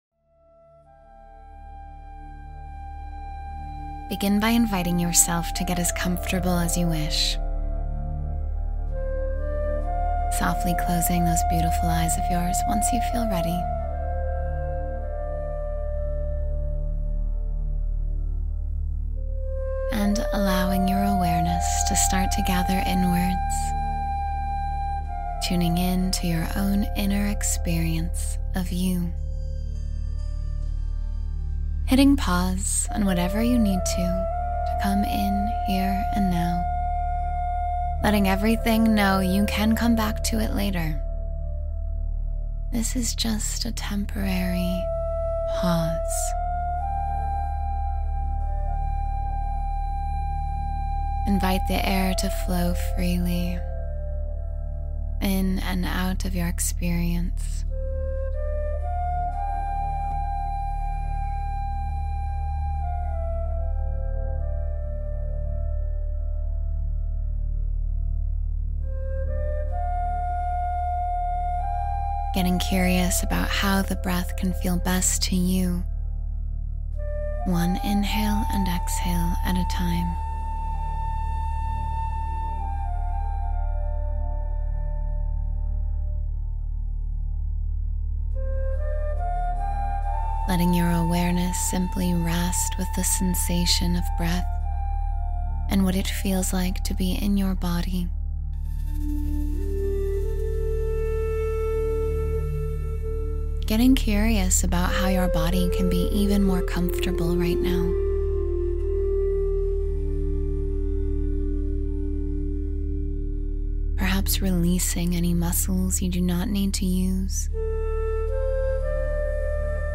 Breathe with Awareness of Ancient Wisdom — Meditation for Deep Connection